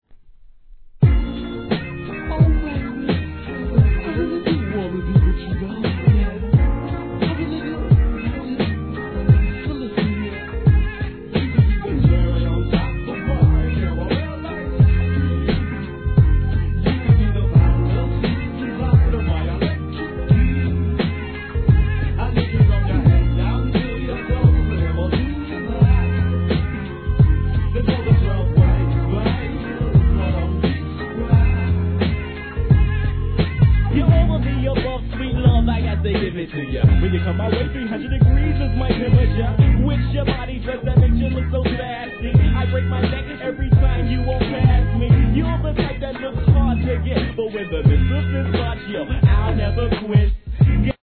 1. HIP HOP/R&B
コーラスフックで聴かせる1995年メロ〜HIP HOP!